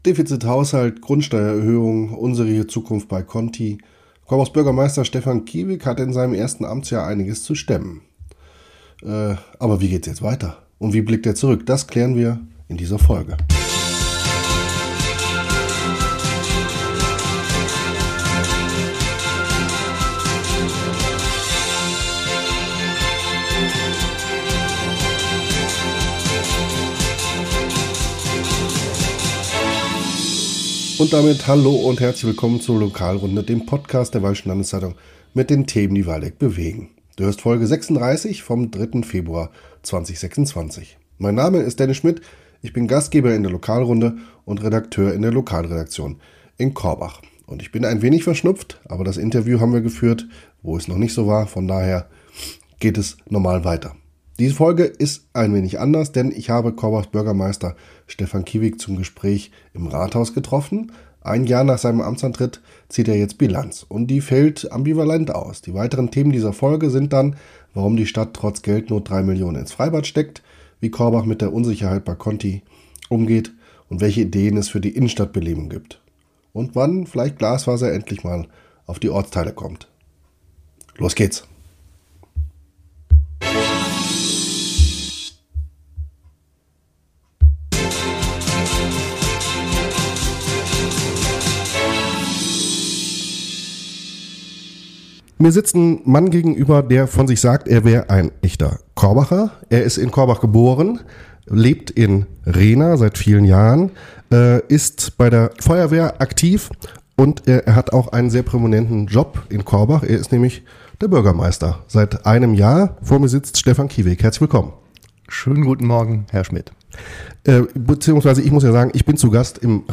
Stefan Kieweg ist seit einem Jahr Bürgermeister von Korbach. Im Interview zieht er Bilanz über zwölf intensive Monate.